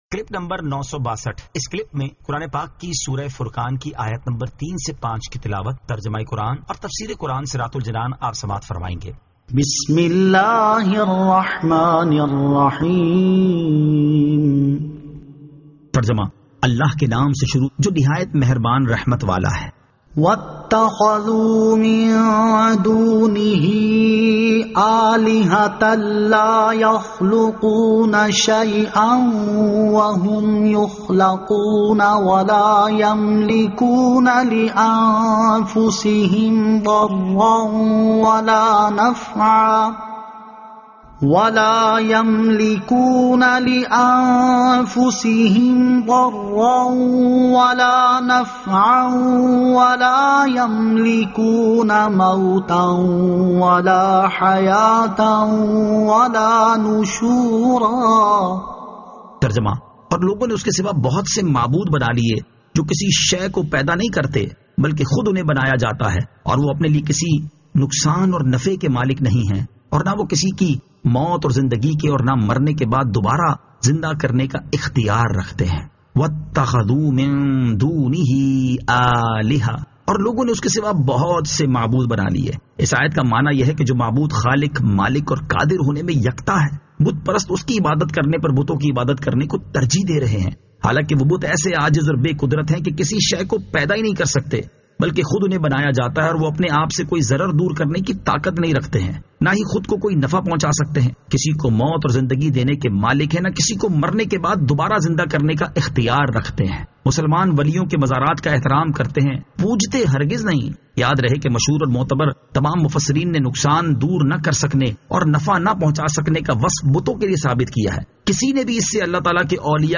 Surah Al-Furqan 03 To 05 Tilawat , Tarjama , Tafseer